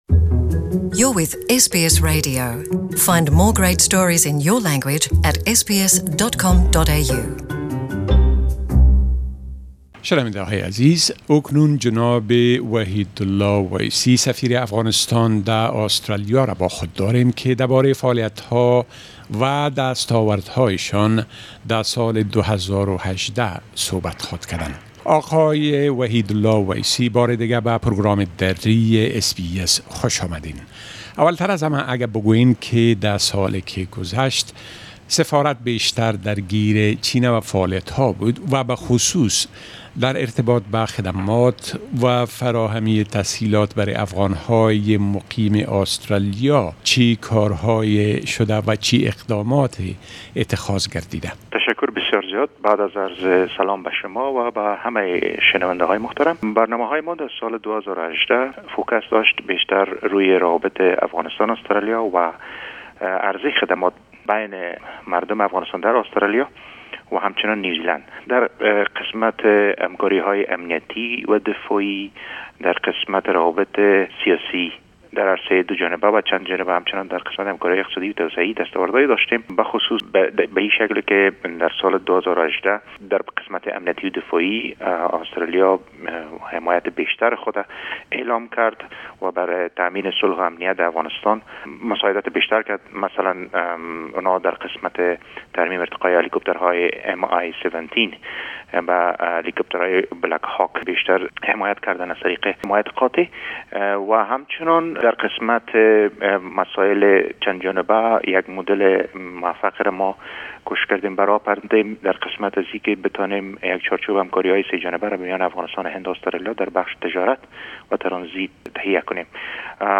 صحبت سفير افغانستان در آسترليا دربارۀ دست آوردهای سفارت افغانستان در ارتباط به مناسبات دو كشور و خدمات بهتر برای افغانها